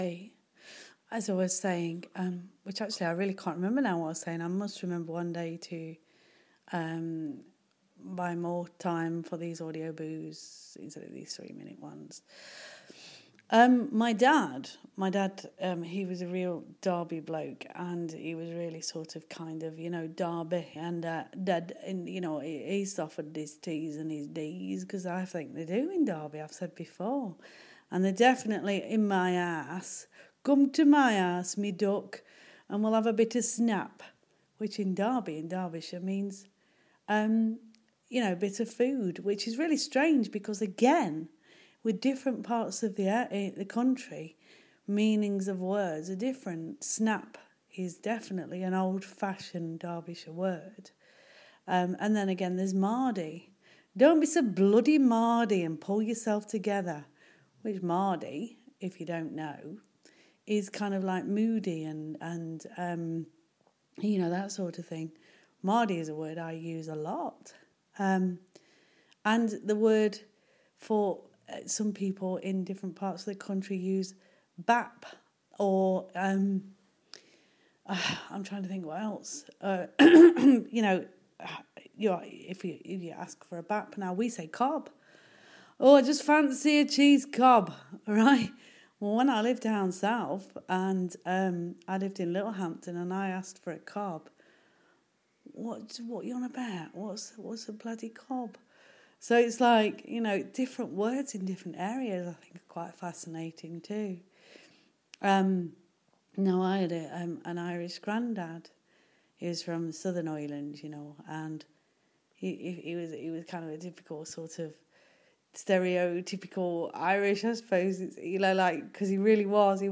Continuation of accents 2.